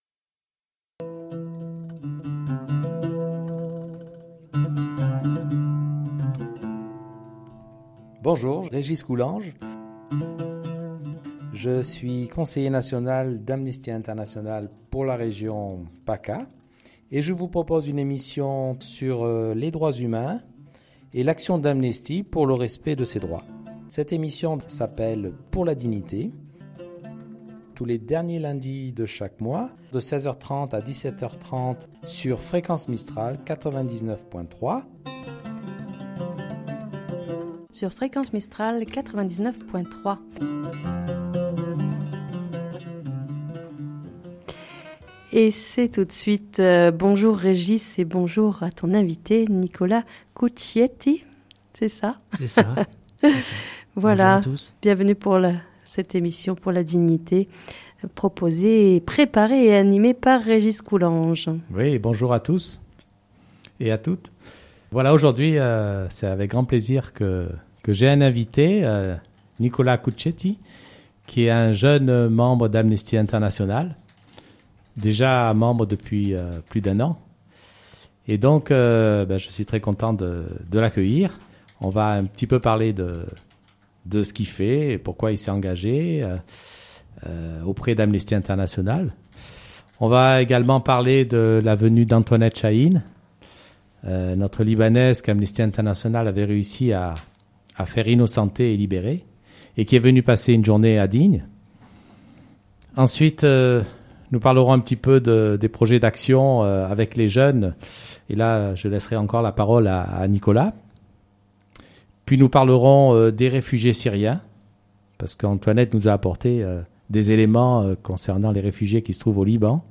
Une émission sur les Droits humains